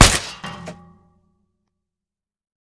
sounds / material / bullet / collide / metall03gr.ogg
metall03gr.ogg